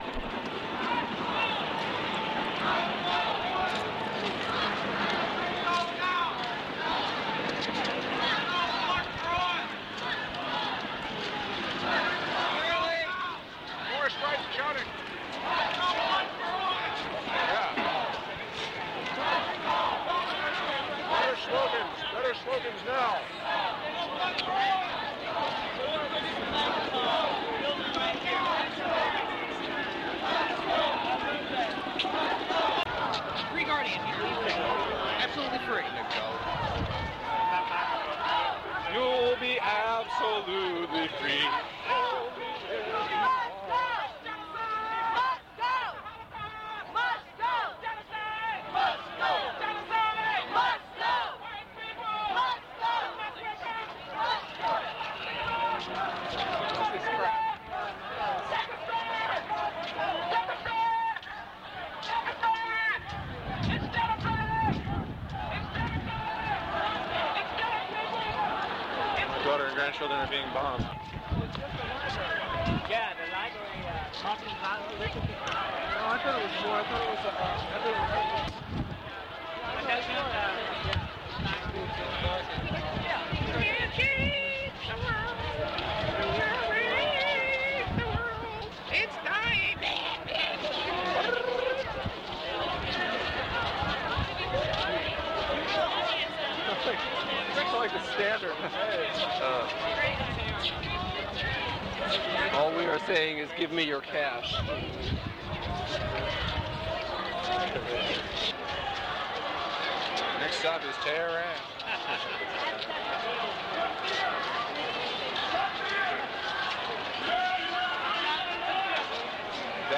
Gulf War protest, 1991, Washington DC